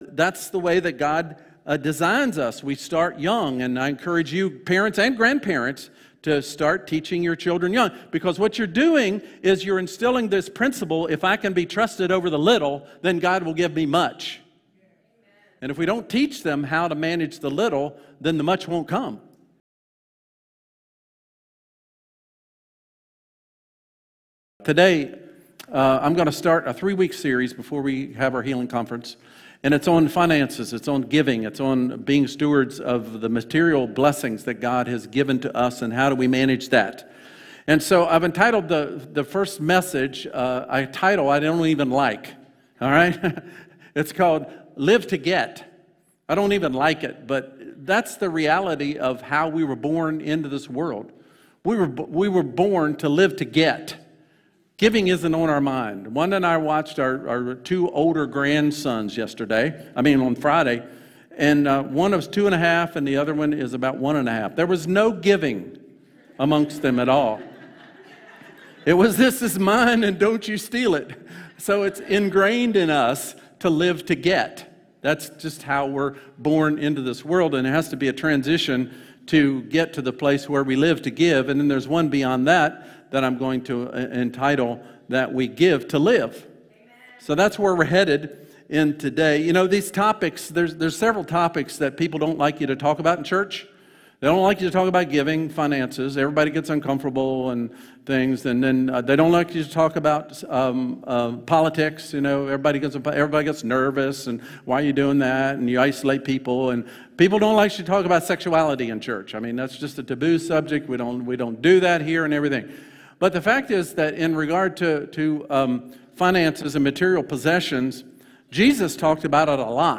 Sermon | Crossroads Community Church